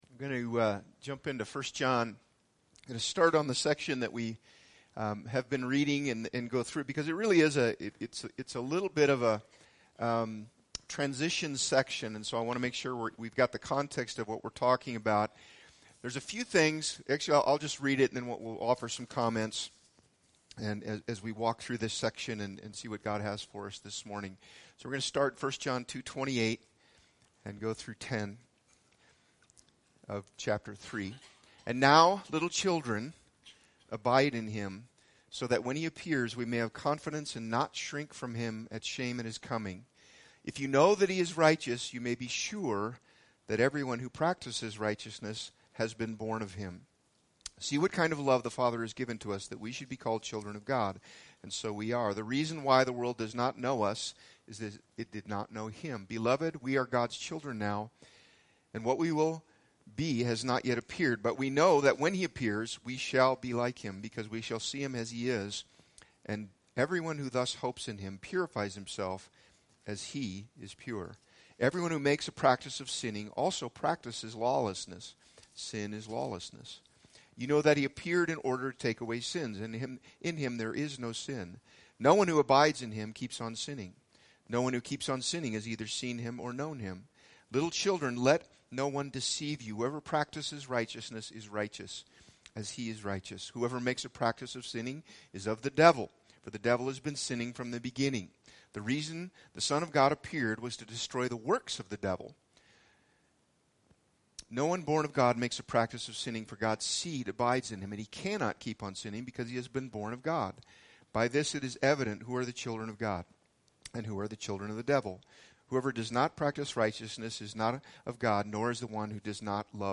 BG Archives Service Type: Sunday Speaker